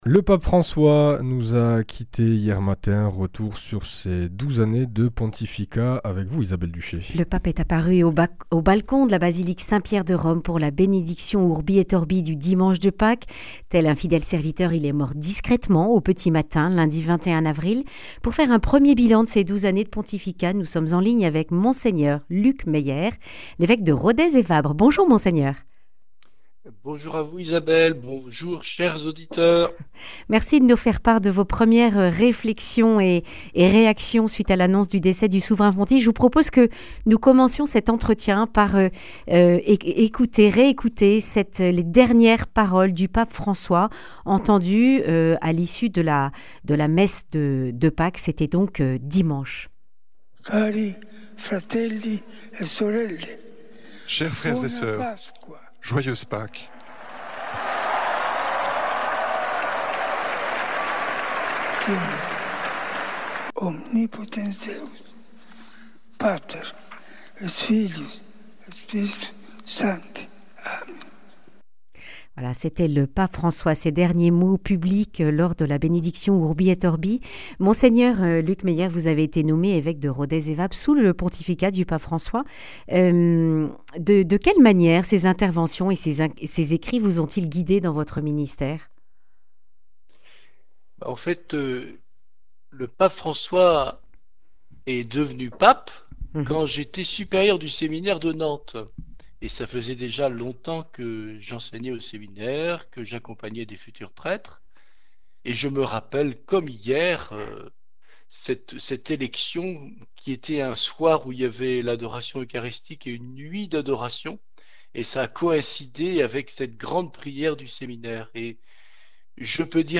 Pour rendre hommage au pape François, dont la mort a été annoncée lundi de Pâques, l’évêque de Rodez et Vabres était l’invité de radio Présence. Monseigneur Luc Meyer a insisté sur le testament spirituel que laisse le Saint Père : un appel à la conversion pour être un chrétien apôtre de la joie.